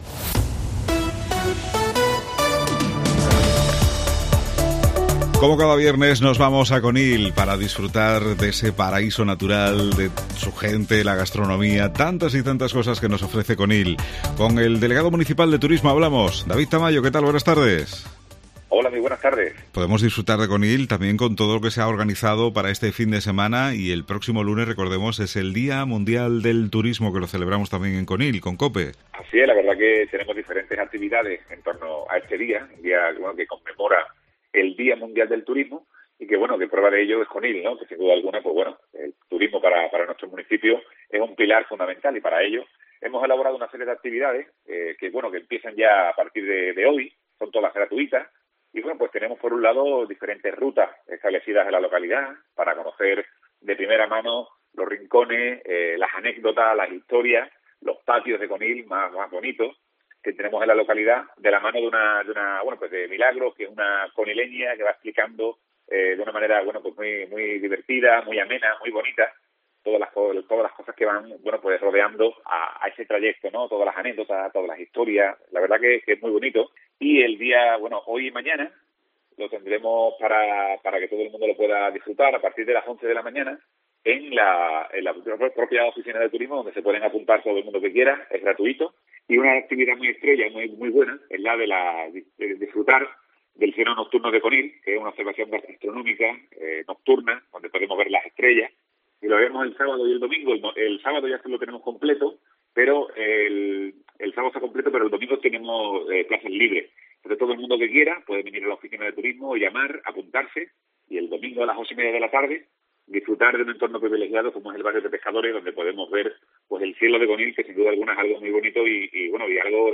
David Tamayo, Deleg Turismo Ayuntamiento de Conil - Día mundial del Turismo